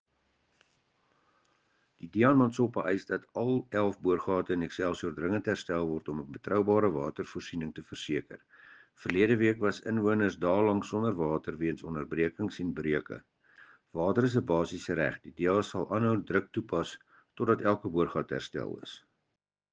Afrikaans soundbites by Cllr Dewald Hattingh and